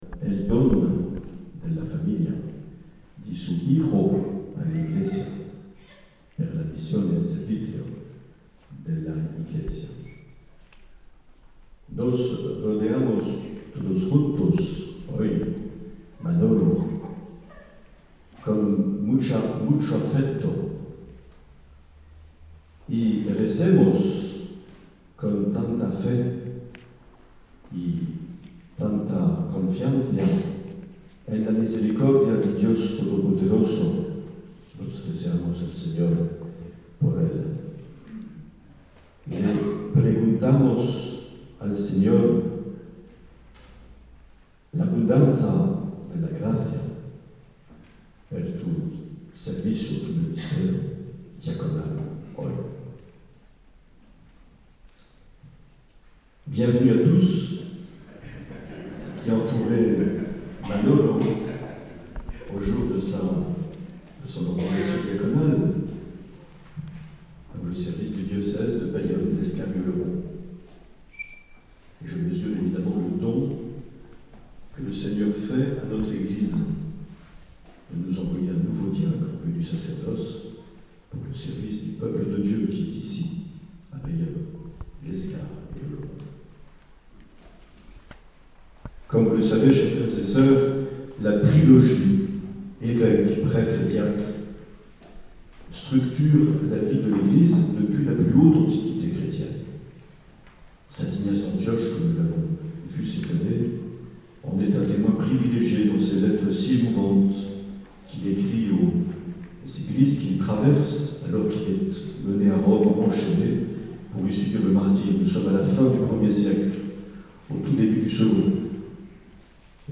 Les Homélies
Une émission présentée par Monseigneur Marc Aillet